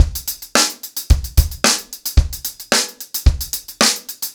TupidCow-110BPM.15.wav